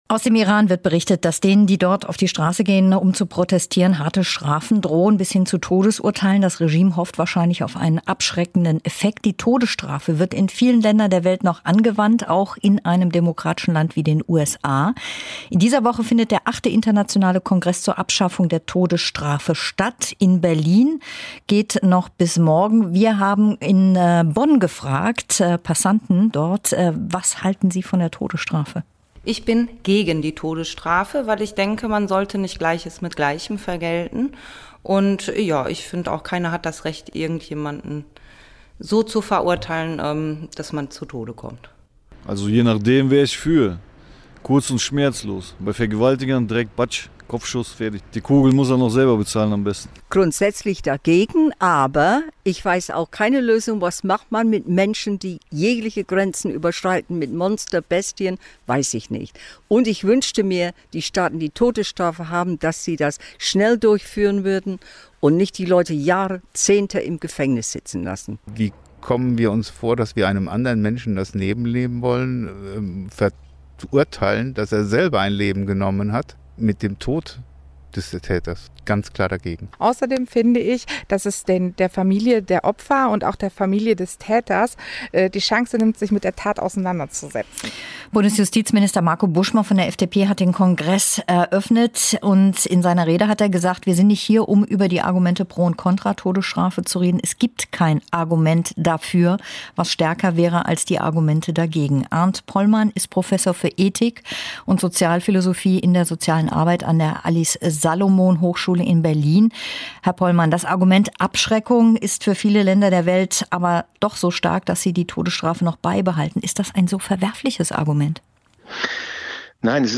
Weltkongress zur Todesstrafe: Abgesandte aus mehr als 125 Staaten debattieren in Berlin über deren Abschaffung. Das Vorhaben ist gefährdet – unter anderem durch „neue Spielarten des Populismus“ in der Politik. Interview in der Sendung „Morgenecho“ auf WDR 5.